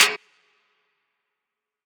DMV3_Snare 12.wav